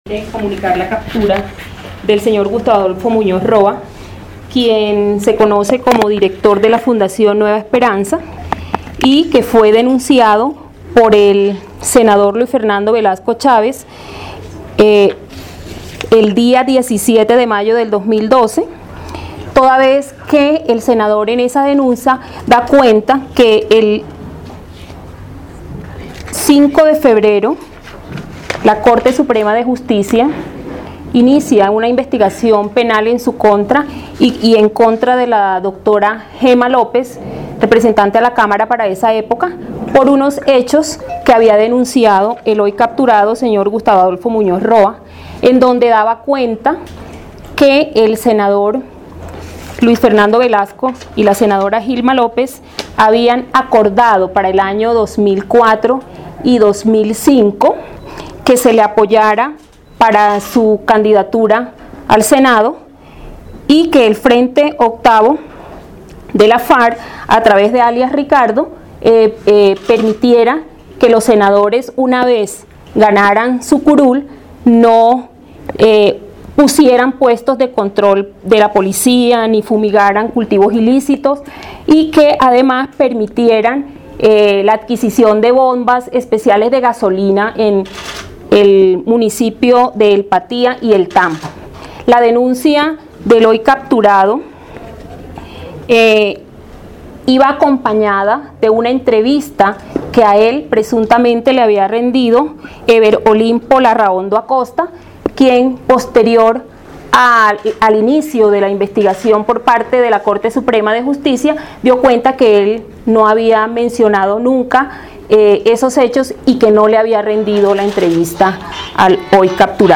Rueda de prensa Gloria Cassiani, fiscal  Grupo de Trabajo para la Investigación de Falsos Testigos
Lugar: Nivel Central Fiscalía General de la Nación. Bogotá, D. C.